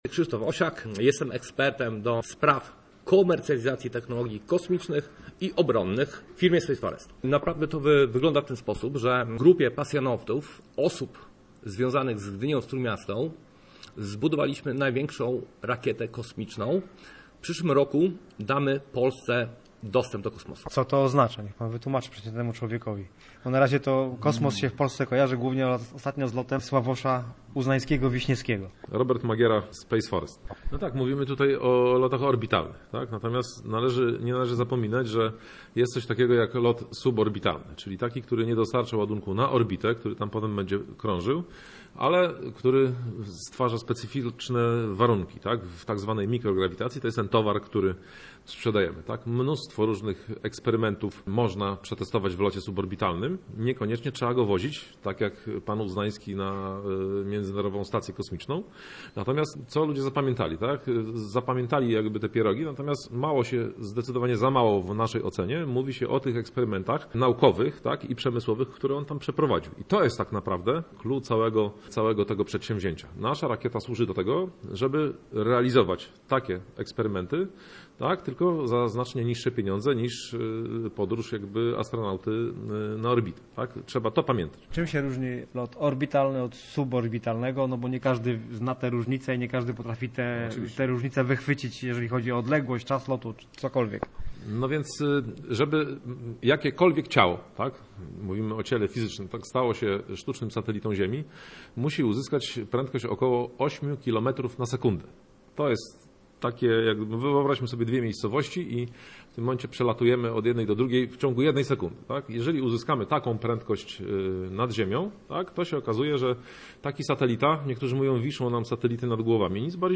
Ustka_port_kosmiczny__rozmowa_OK.mp3